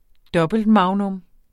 Udtale [ -ˌmɑwnɔm ]